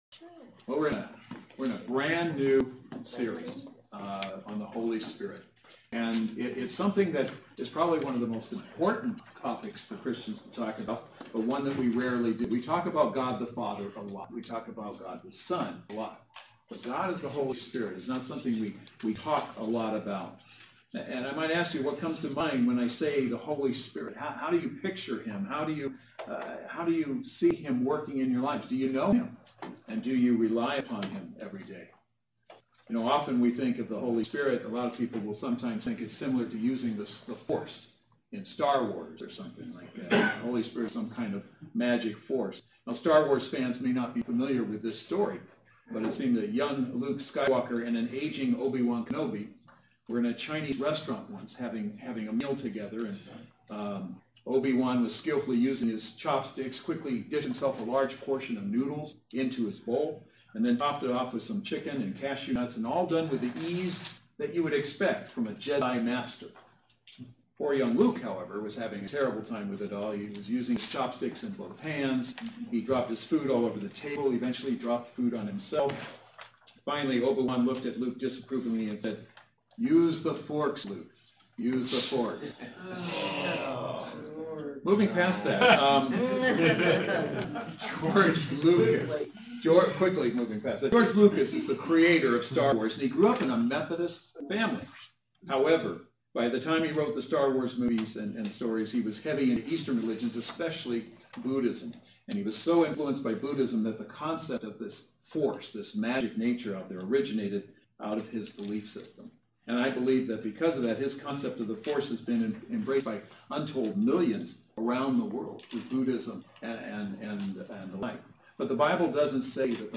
The Holy Spirit Service Type: Saturday Worship Service Speaker